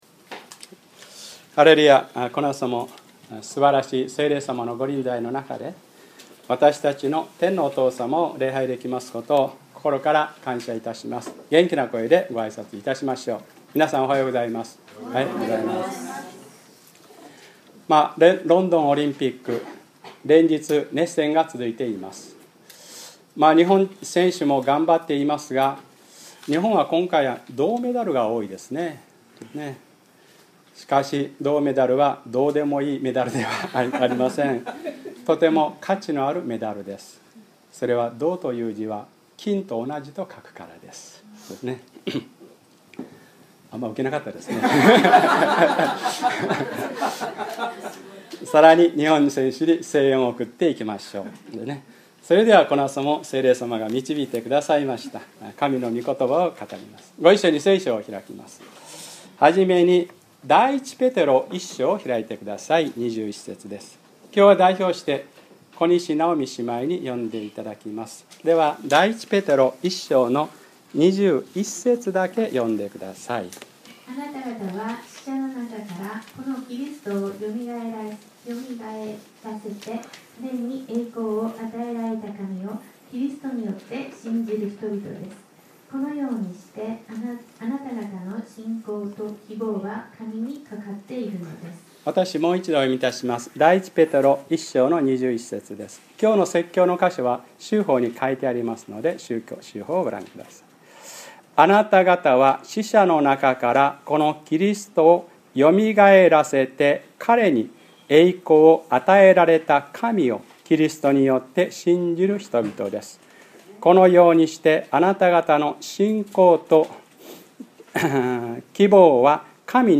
2012年8月5日(日）礼拝説教 「希望があれば生きていける『信仰と希望は神にかかっているのです』」